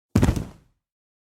Sticking the Landing Feet Hit
SFX
yt_LLEQktMcocY_sticking_the_landing_feet_hit.mp3